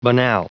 Prononciation du mot banal en anglais (fichier audio)
Prononciation du mot : banal